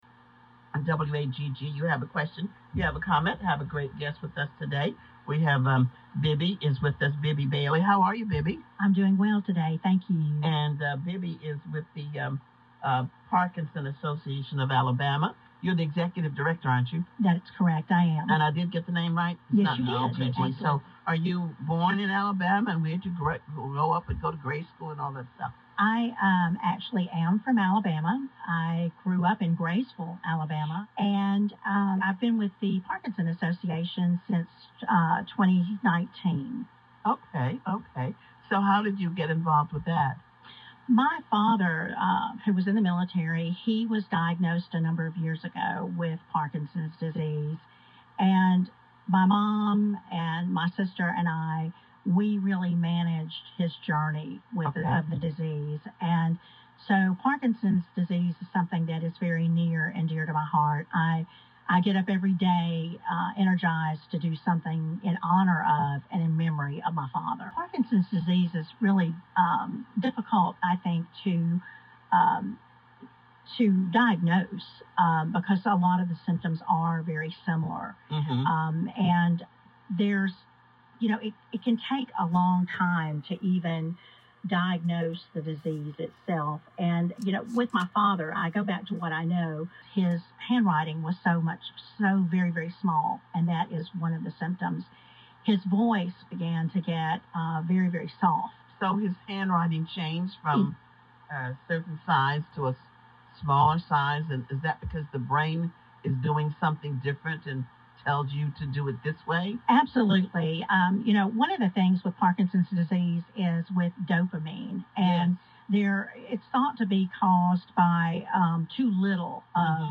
The link to the radio interview is down below